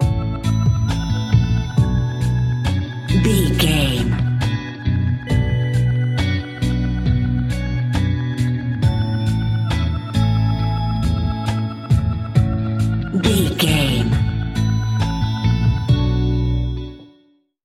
Ionian/Major
A♭
chilled
laid back
Lounge
sparse
new age
chilled electronica
ambient
atmospheric